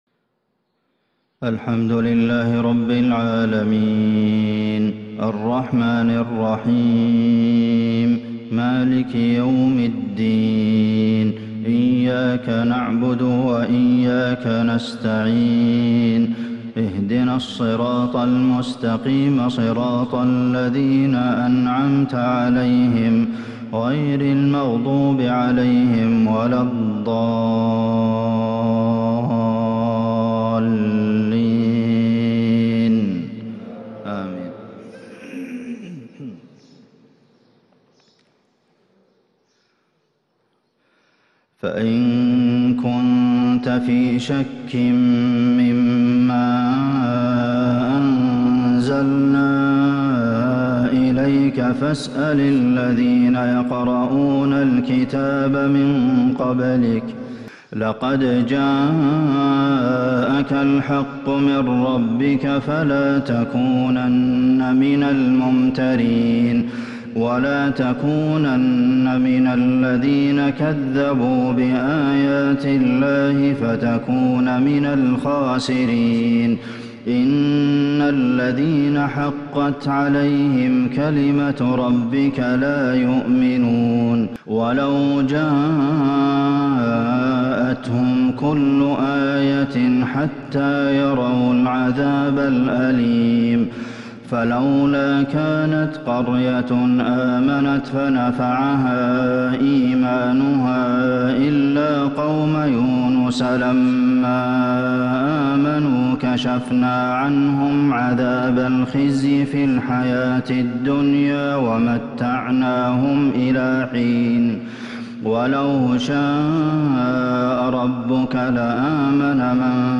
فجر الأحد 2-7-1442هـ من سورة يونس | Fajr prayer from Surah Yunus 14/2/2021 > 1442 🕌 > الفروض - تلاوات الحرمين